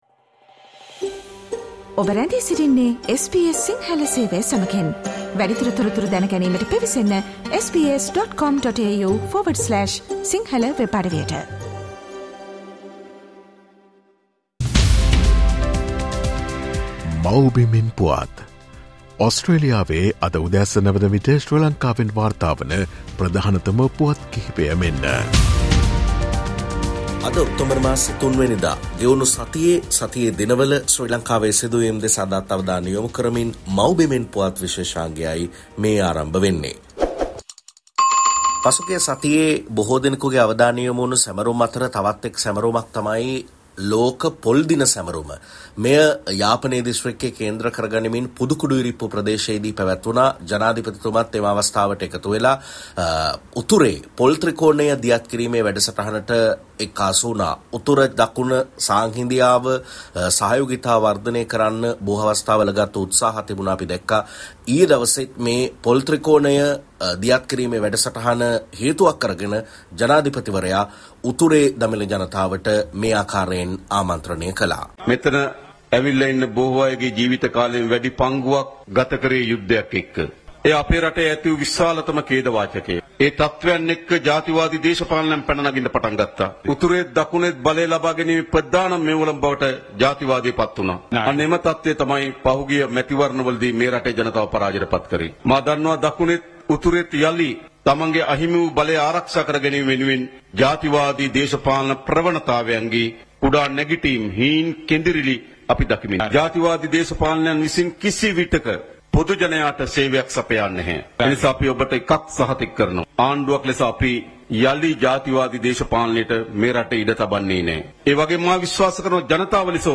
මේ සතියේ ශ්‍රී ලංකාවෙන් වාර්තා වූ උණුසුම් හා වැදගත් පුවත් සම්පිණ්ඩනය.